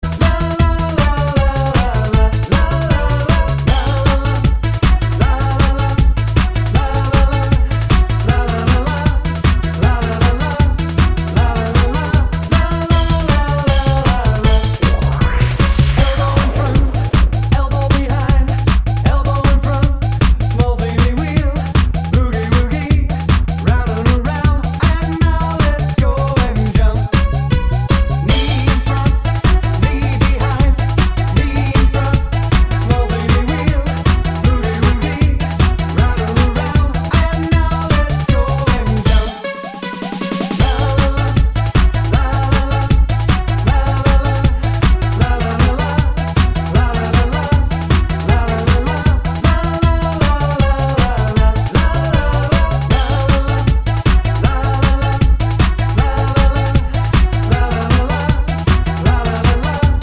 with No Backing Vocals